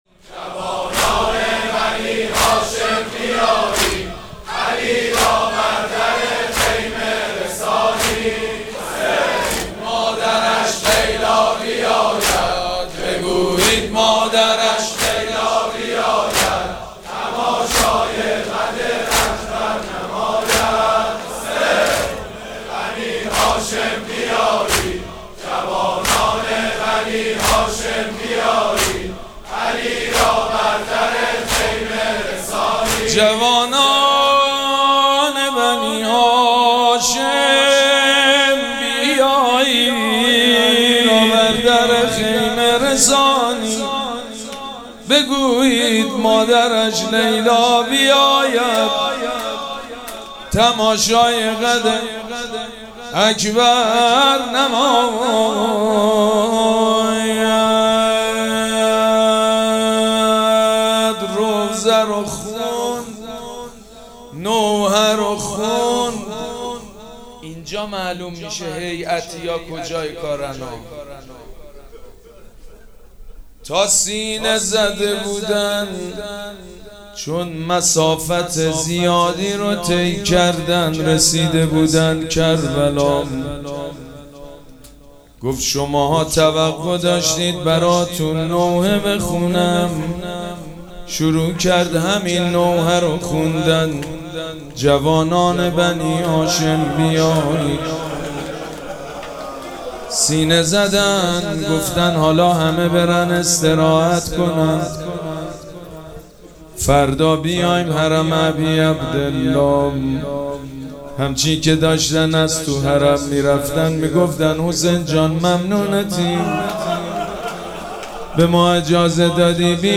محفل عزاداری شب هشتم محرم
مداحی